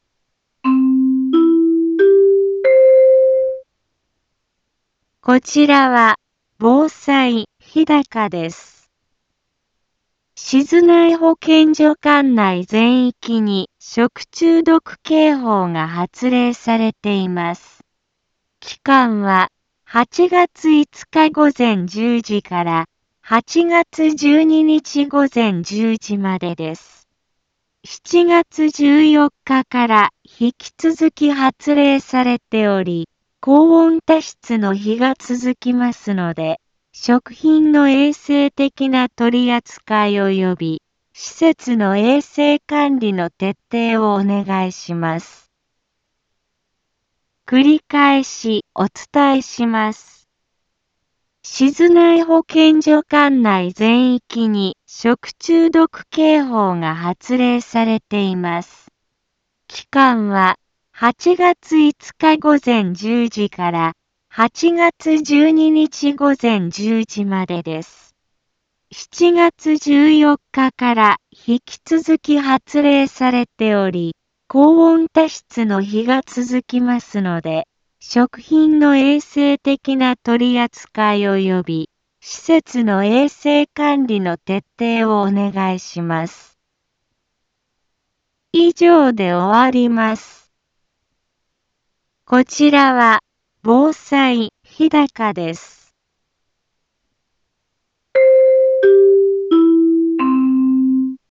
Back Home 一般放送情報 音声放送 再生 一般放送情報 登録日時：2025-08-06 10:03:34 タイトル：食中毒警報の発令について インフォメーション： こちらは、防災日高です。 静内保健所管内全域に食中毒警報が発令されています。